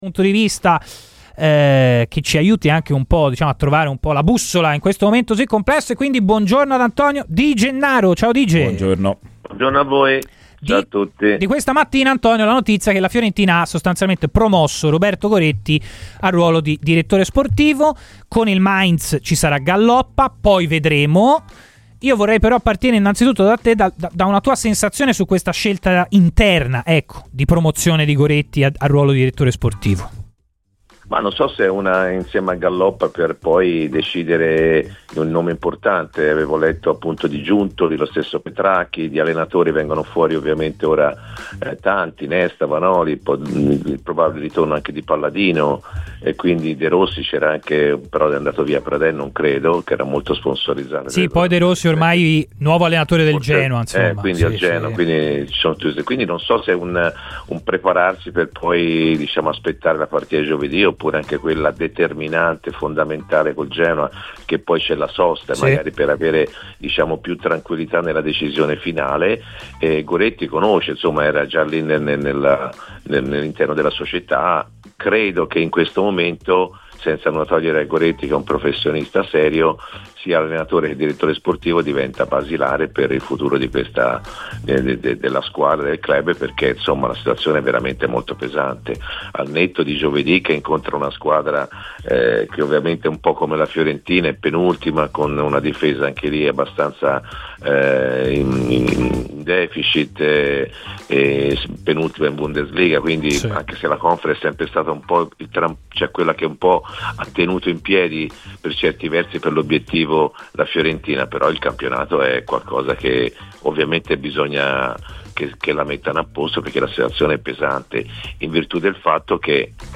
Antonio Di Gennaro, ex centrocampista della Fiorentina ha parlato ai microfoni di Radio FirenzeViola nel corso di "Chi si Compra?" in vista del match contro il Mainz: "La promozione di Goretti non so se è una scelta temporanea come Galloppa.